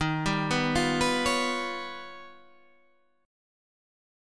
D# Augmented 9th